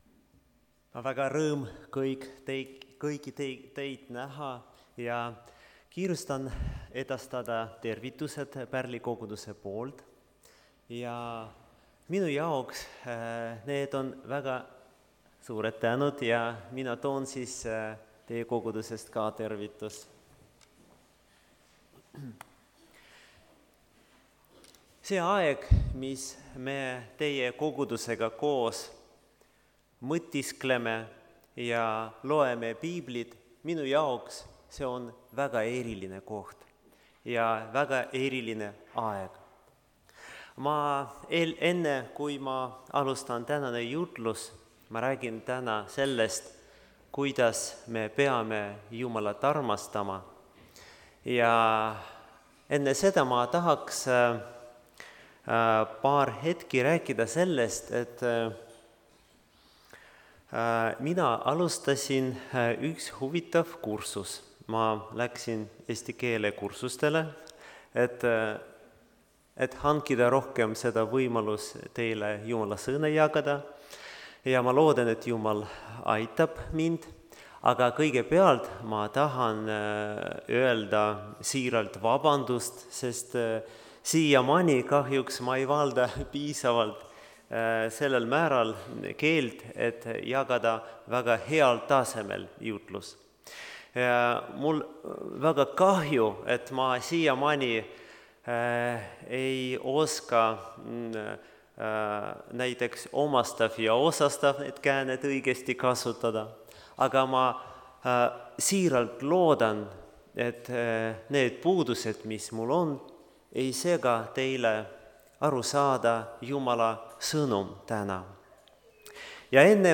(Tallinnas)
Jutlused